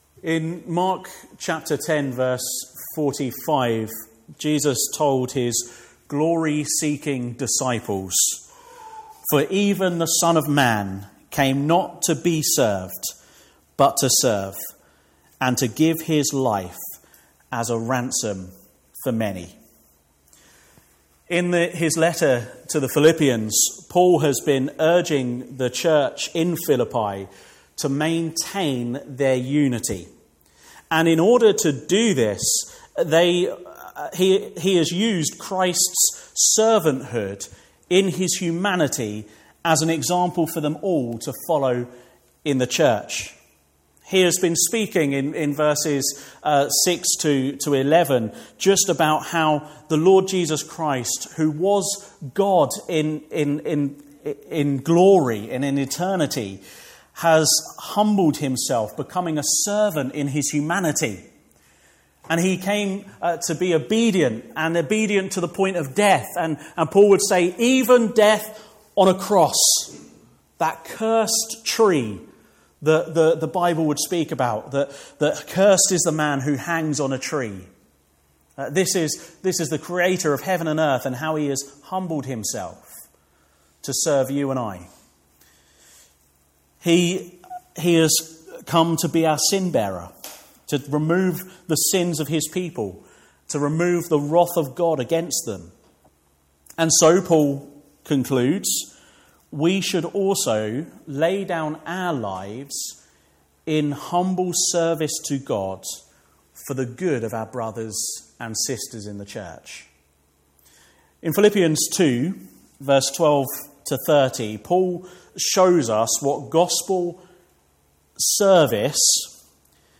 Rejoicing In Gospel Service